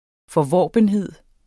Udtale [ fʌˈvɒˀbənˌheðˀ ]